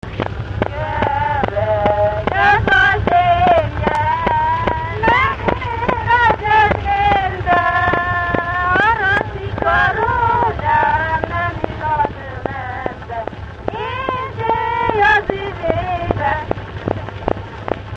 Dallampélda: Kerek ez a zsemlye
ének Gyűjtő